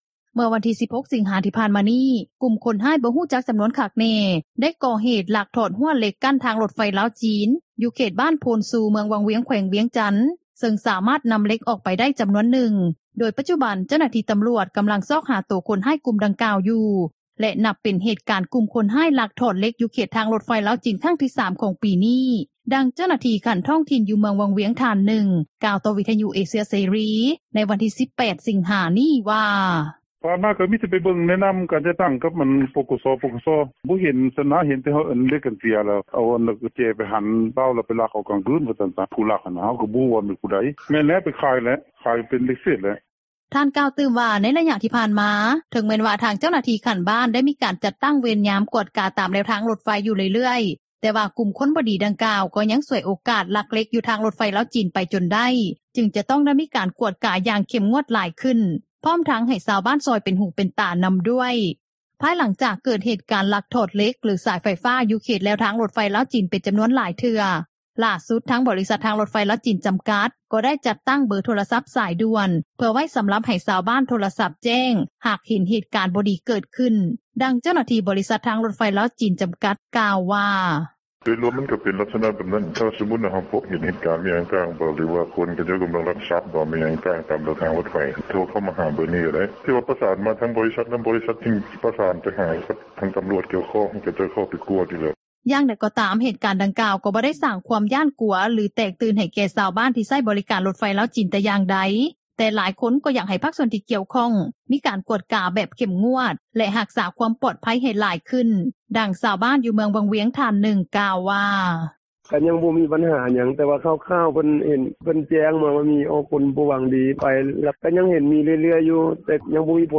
ດັ່ງຊາວບ້ານ ຢູ່ເມືອງວັງວຽງ ທ່ານນຶ່ງ ກ່າວວ່າ:
ດັ່ງຊາວບ້ານ ຢູ່ນະຄອນຫຼວງວຽງຈັນ ທ່ານນຶ່ງ ກ່າວວ່າ: